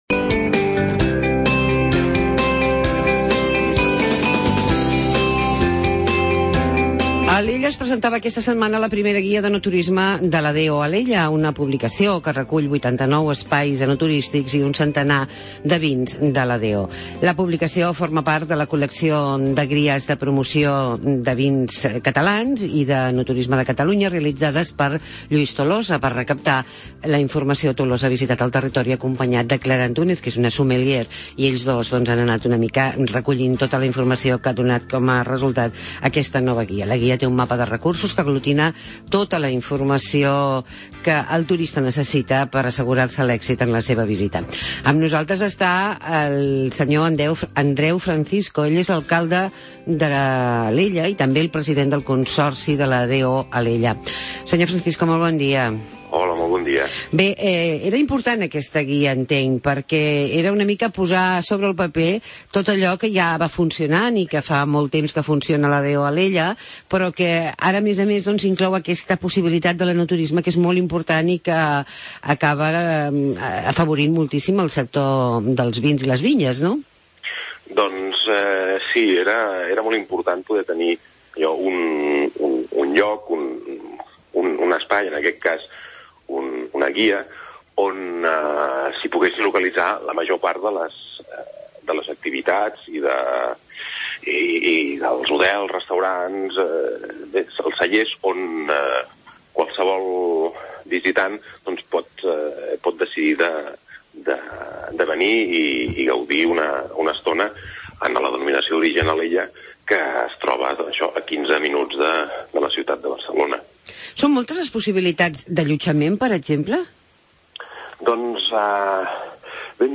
Entrevista amb Andreu Francisco, alcalde de Alella.